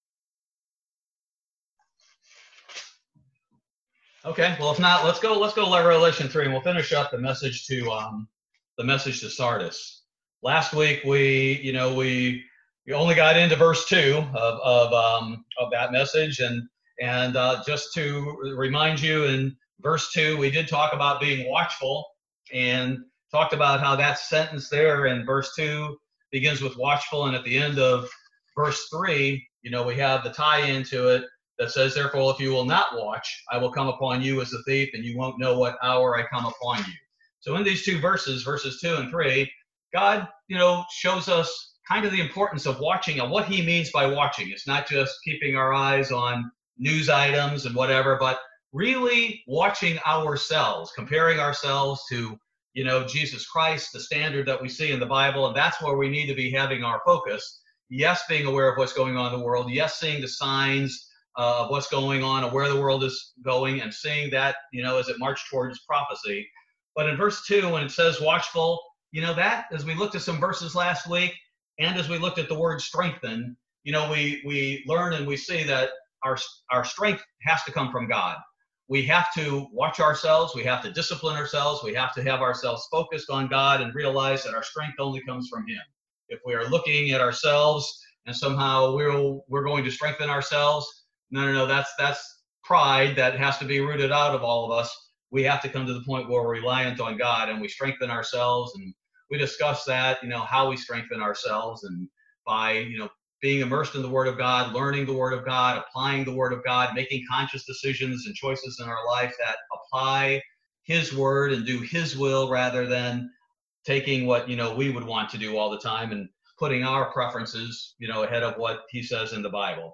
Bible Study - August 12, 2020